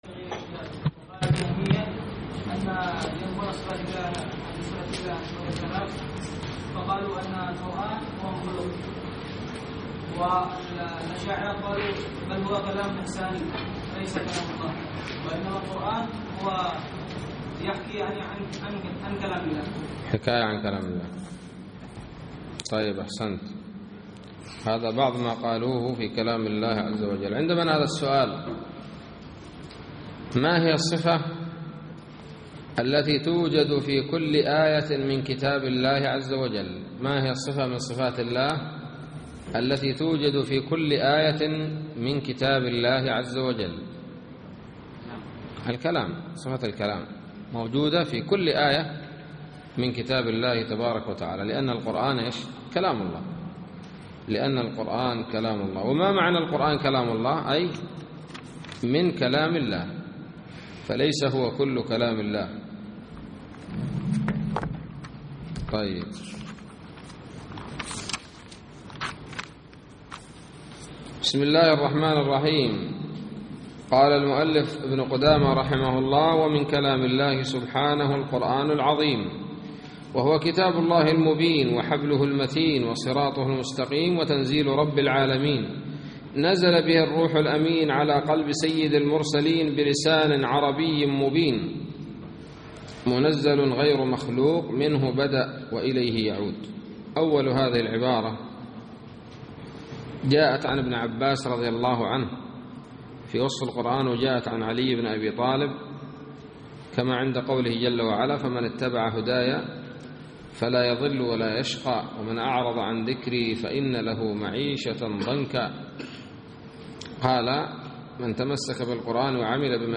الدرس الثامن والعشرون من شرح لمعة الاعتقاد